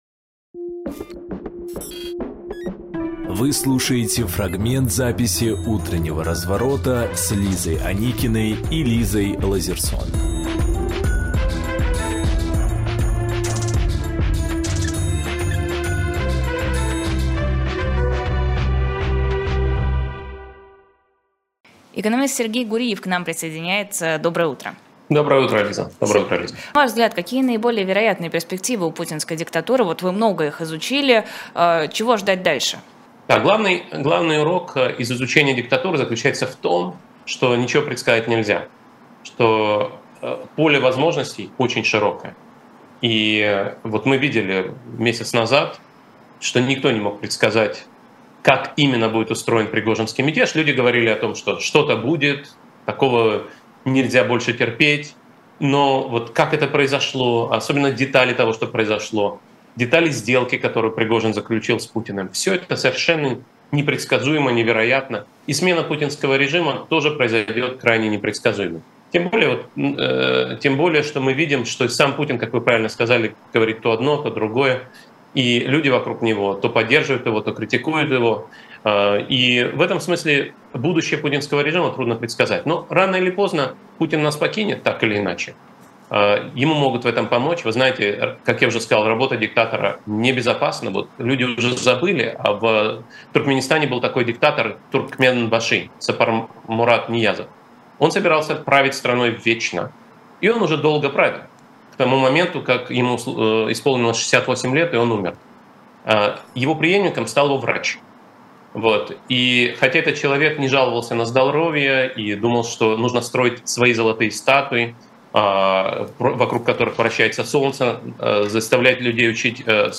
Сергей Гуриевэкономист, профессор Парижского университета Sciences Po
Фрагмент эфира от 23.07.23